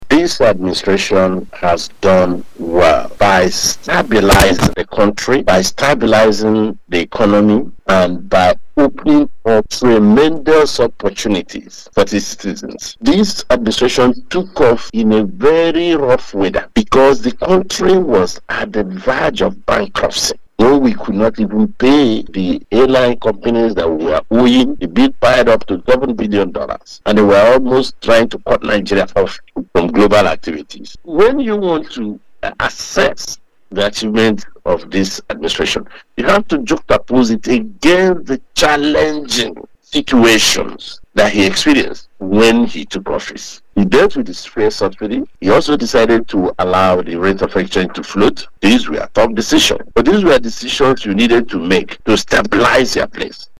This was made known by a Chieftain of the APC and the Member representing the South East on the board of the North East Commission Hon. Sam Onuigbo who was a guest on Family Love FM’s Open Parliament monitored by Dailytrailnews.